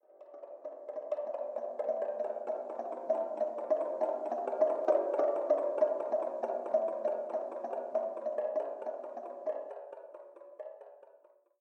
Fingerdrloop5.mp3